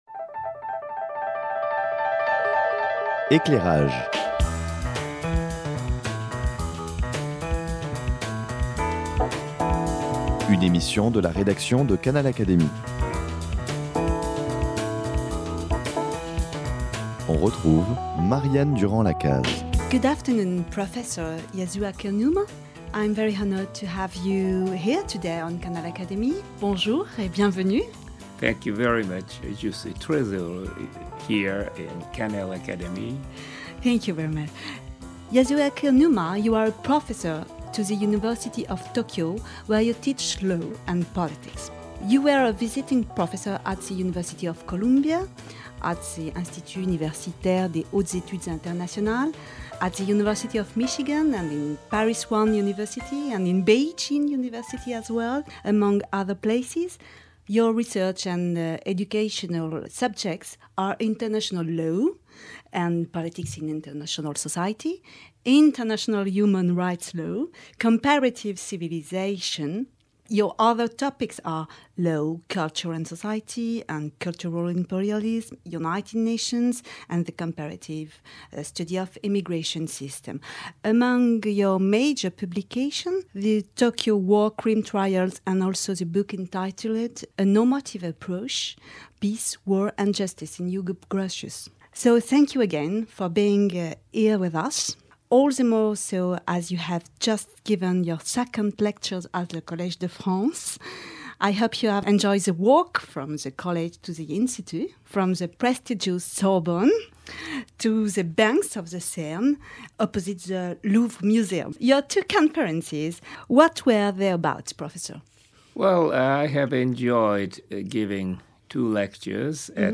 Il expose, dans cet entretien en anglais, l’objet de ses travaux de recherche sur les droits de l’homme.